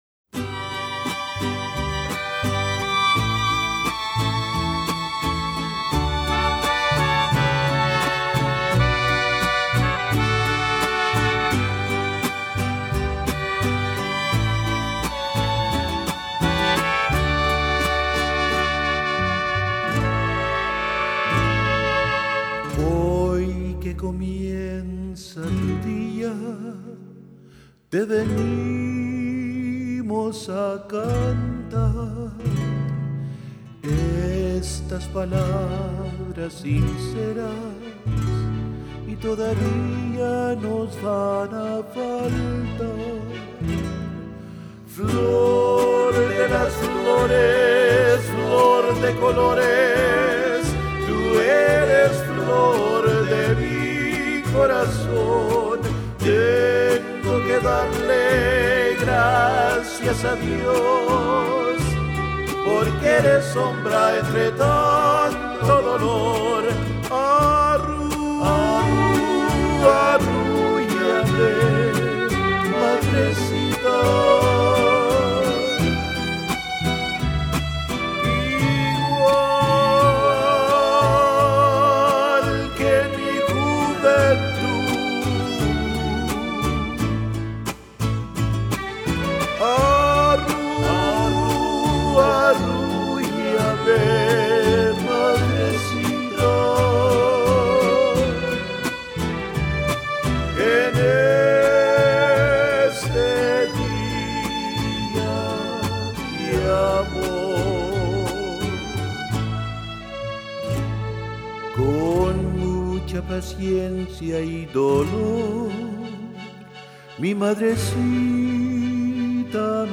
Voicing: Three-part equal; Solo; Assembly